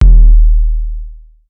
Waka KICK Edited (26).wav